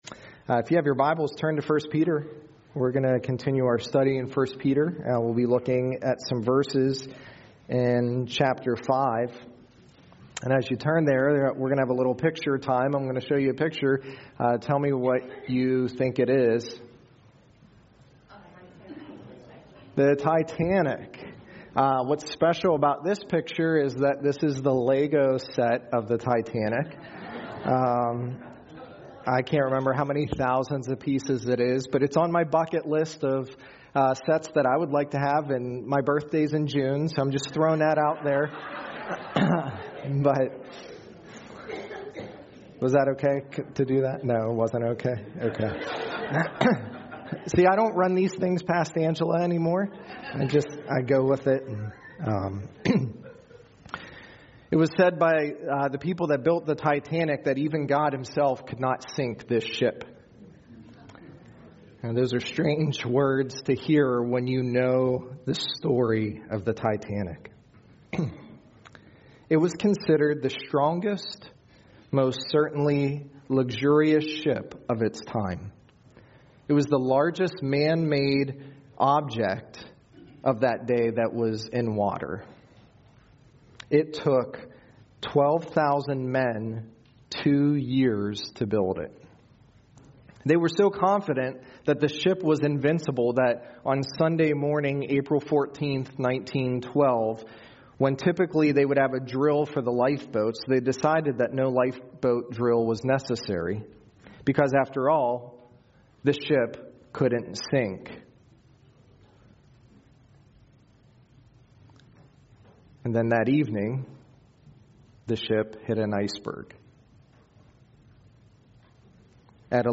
Sermons | North Annville Bible Church